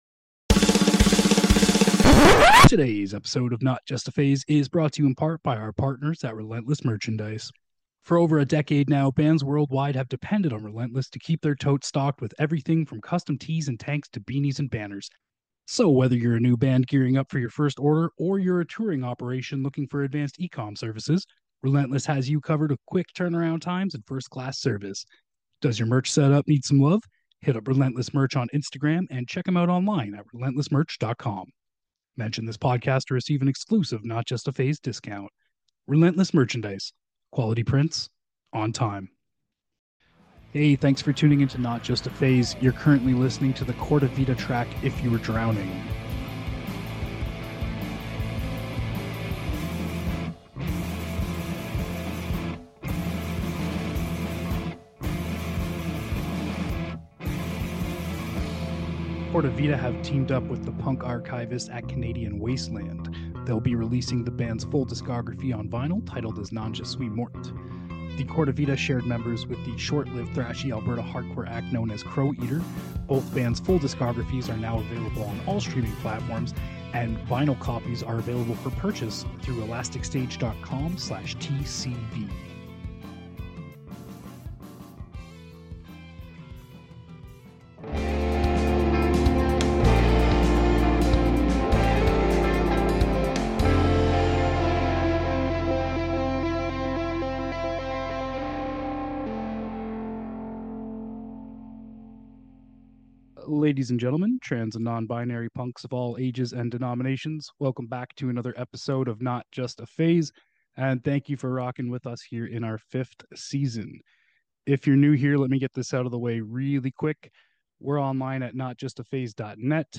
A punk show interviewing artists and labels that produce various music genres.